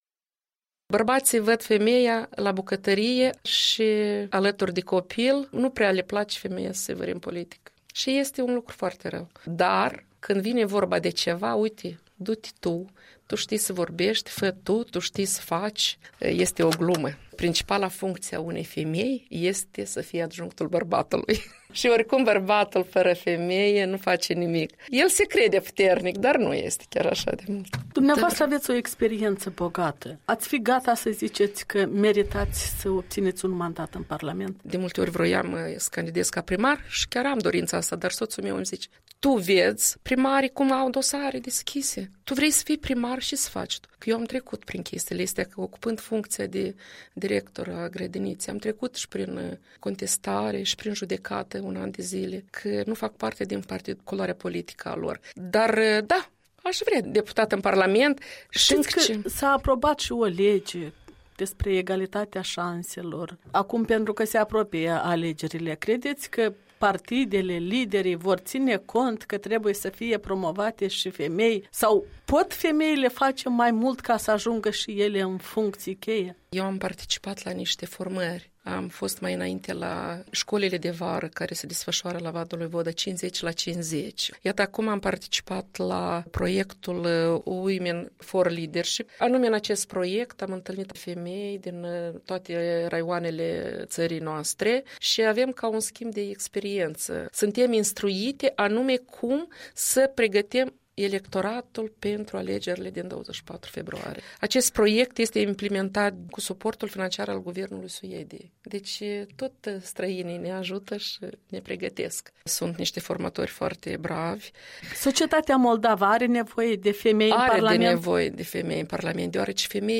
Despre participarea activă a femeilor la luarea deciziilor în viața publică, un interviu cu managerul unei grădinițe dintr-un sat al Moldovei.